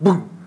Explo_1.wav